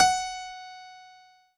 PIANO5-10.wav